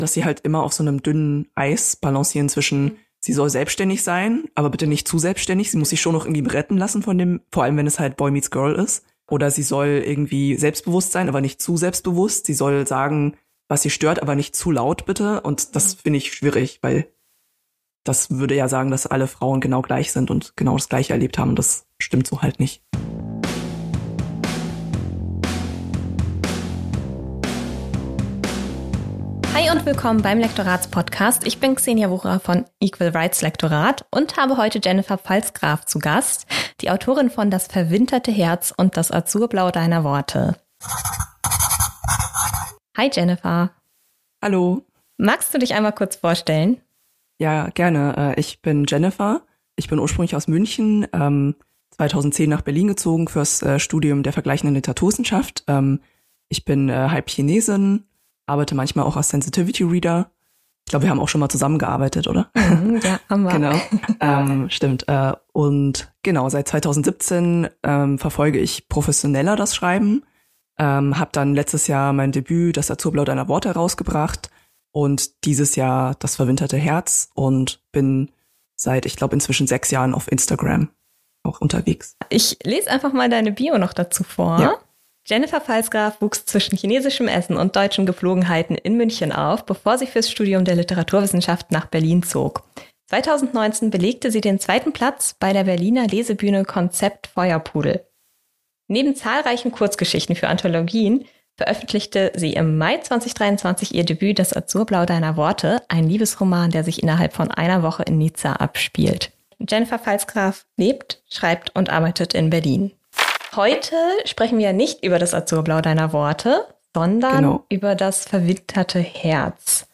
#14 Lektorin interviewt Autorin